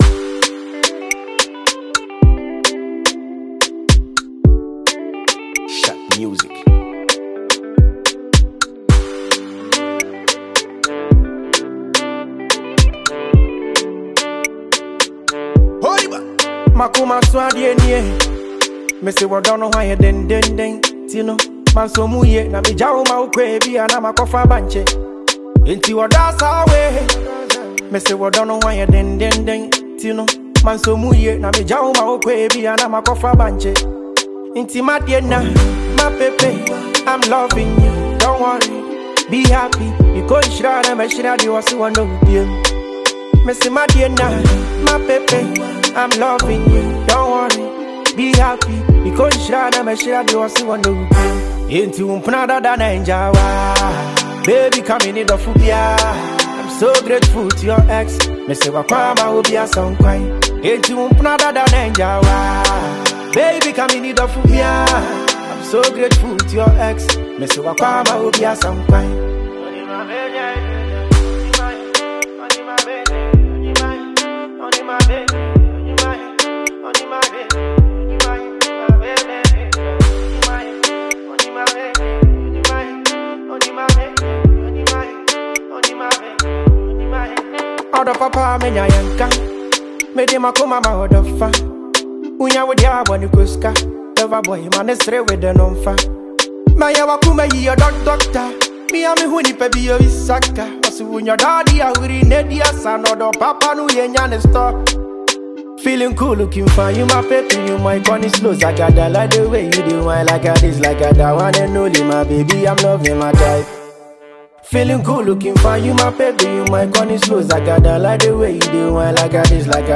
Prominent Ghanaian Singer